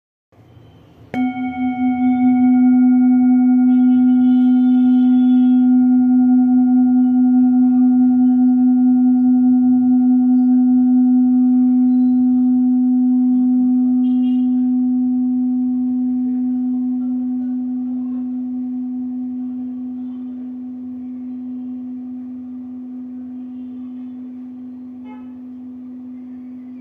Singing Bowl-25535
Buddhist Handmade Jambati Singing Bowl with Amoghasiddhi Buddha Design, Fine Carving, Select Accessories , A bowl used for meditation and healing, producing a soothing sound that promotes relaxation and mindfulness
Material Bronze